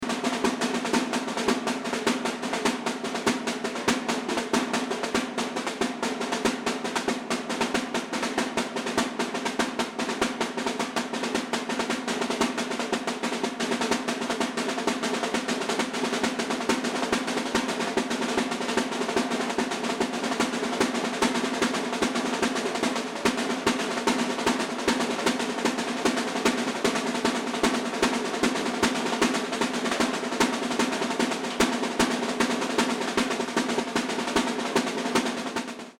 Site d'audios et de partitions sur les percussions br�siliennes jou�es dans une batucada.
levadabasicacaixa.mp3